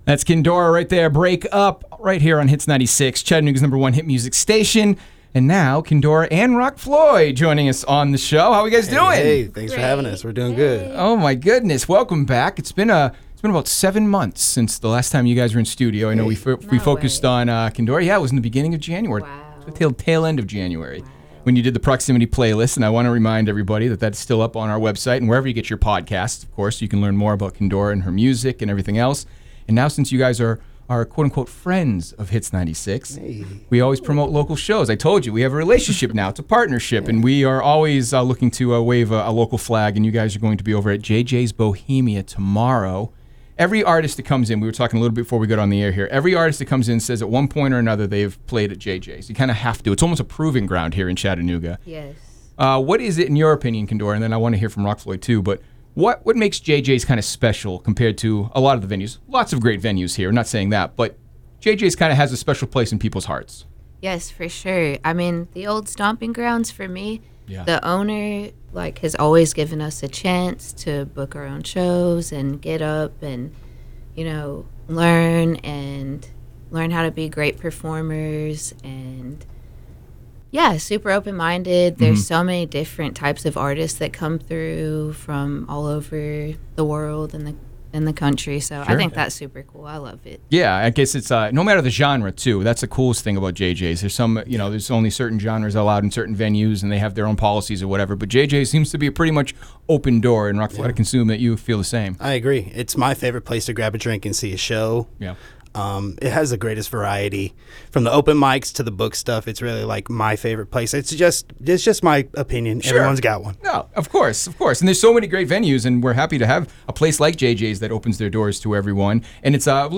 Interview.wav